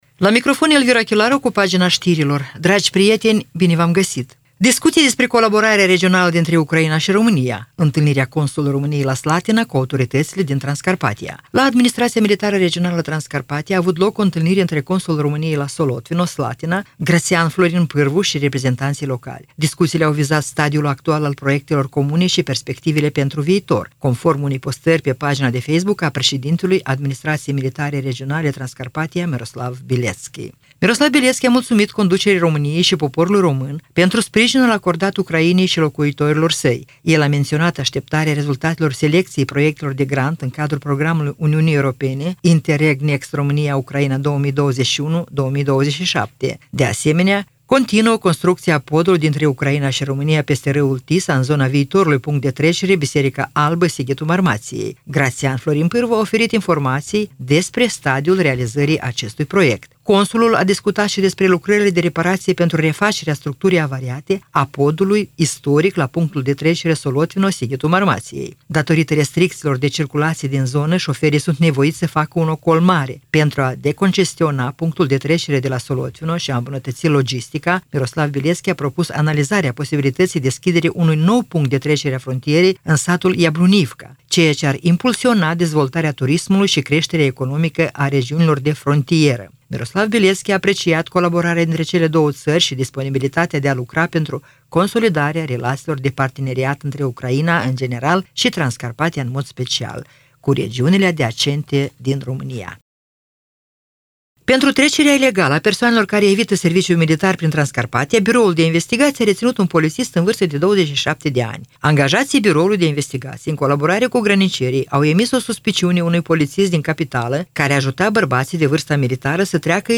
Știri Radio Ujgorod – 15.11.2024
Știri de la Radio Ujgorod.